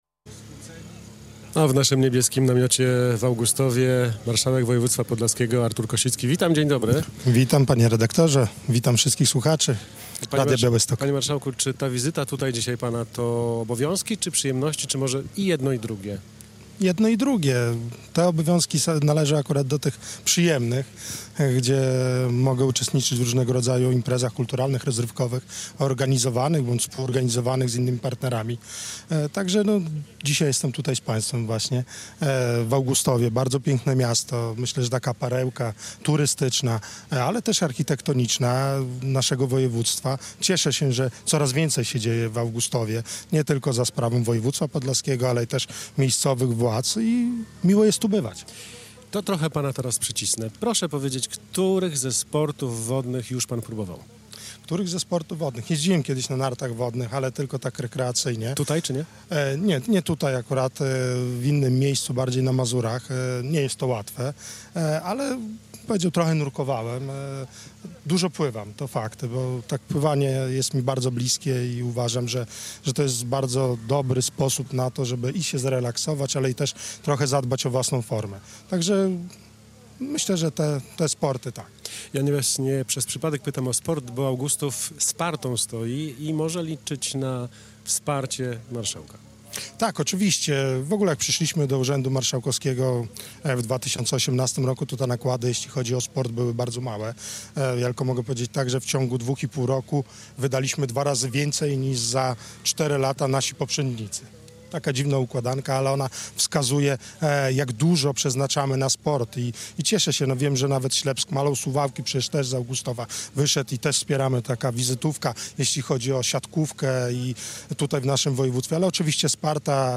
Z marszałkiem województwa podlaskiego
Wprost znad jeziora Necko nadawaliśmy nasz program od 13:00 do 17:00. Plenerowe studio rozstawiliśmy przy plaży miejskiej, w okolicach parkingu Wyciągu Nart Wodnych.